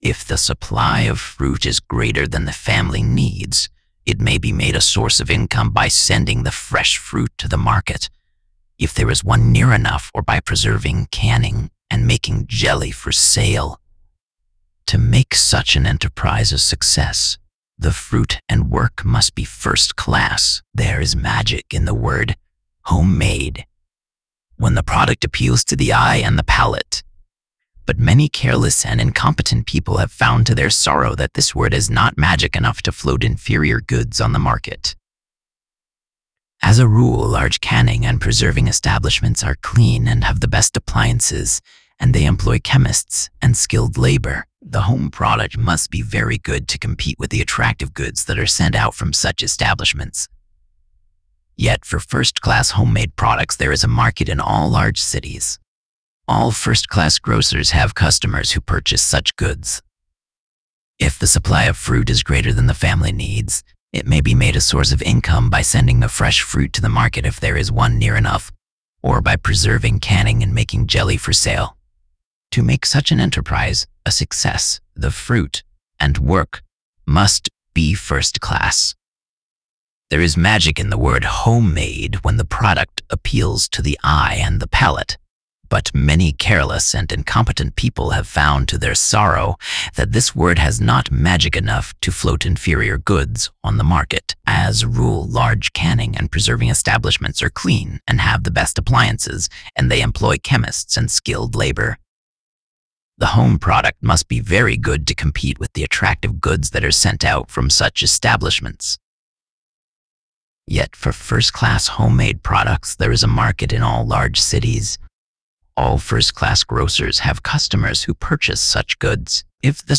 audio-to-audio text-to-speech voice-cloning
"mode": "cross_lingual",
"speed": 1,